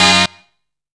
HARD HIT.wav